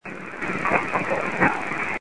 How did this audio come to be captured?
All are Class C to B quality voices. The following have been amplified and spikes from the recorder have been reduced: